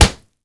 punch_head_weapon_bat_impact_05.wav